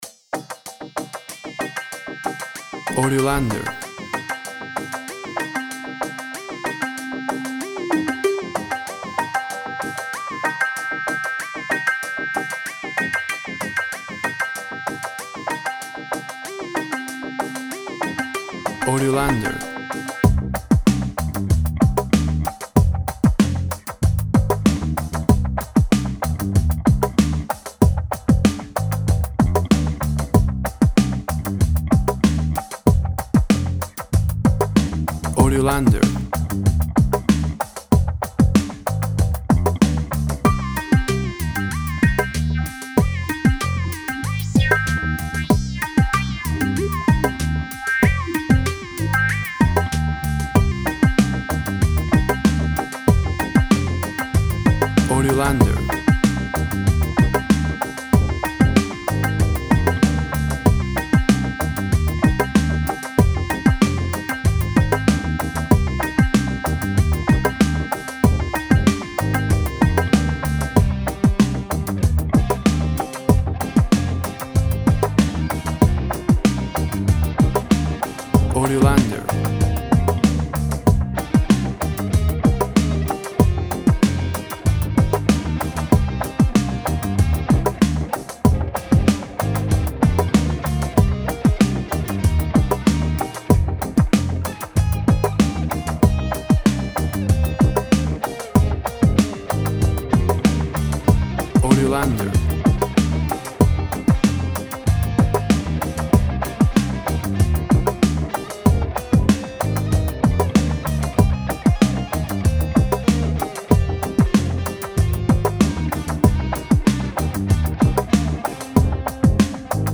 Emtion music, latin urban groove.
Tempo (BPM) 95